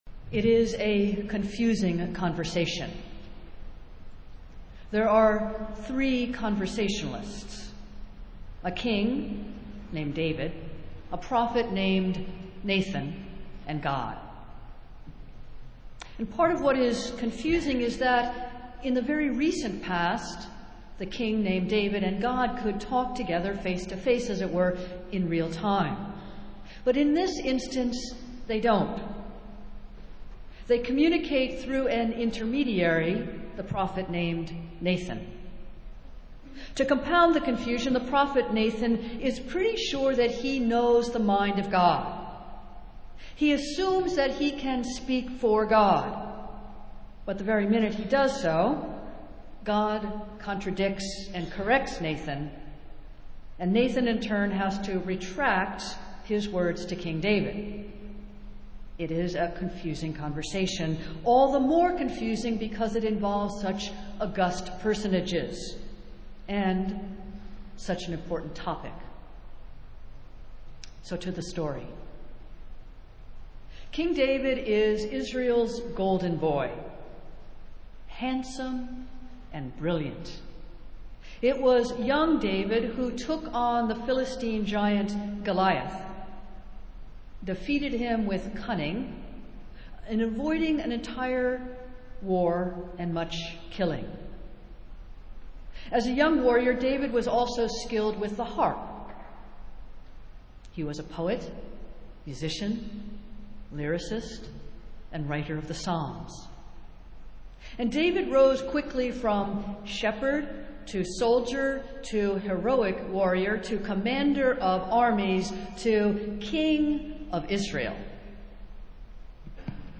Festival Worship - Eighth Sunday after Pentecost